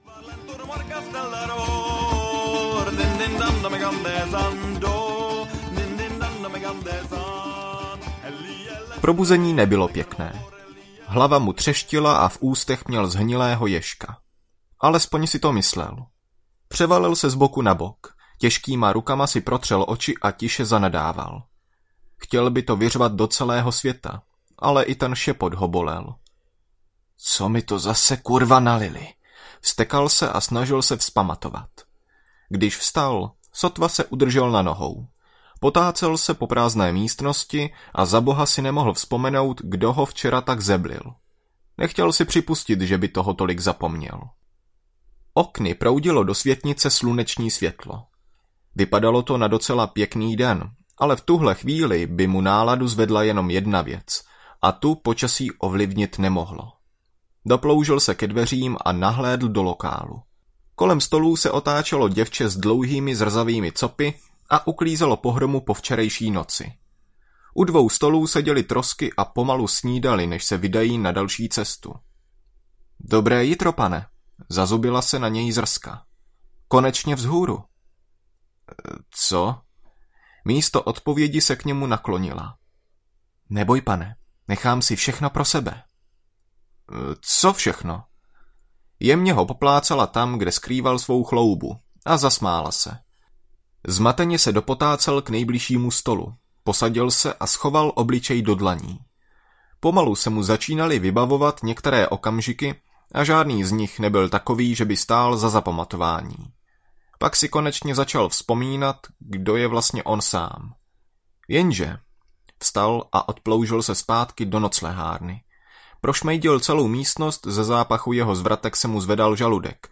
Hlas Černého hvozdu audiokniha
Ukázka z knihy